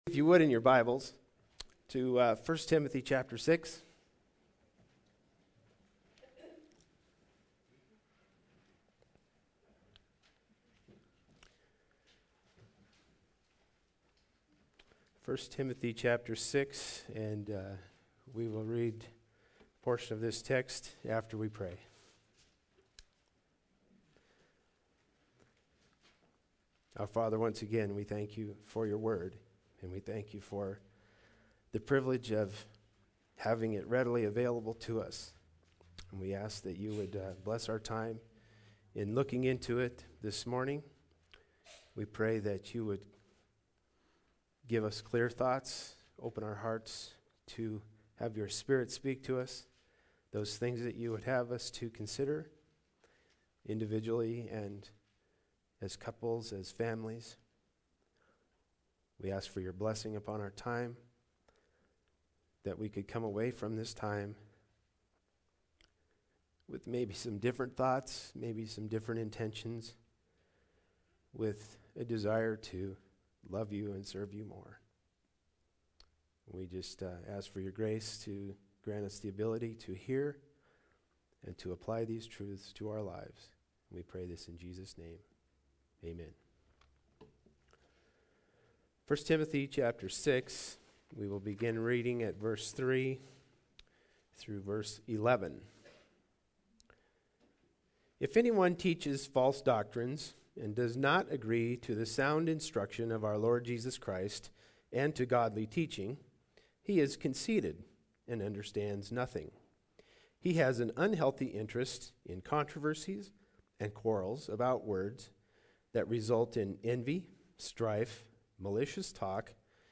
1 Timothy 6:3-11 Service Type: Sunday Service Bible Text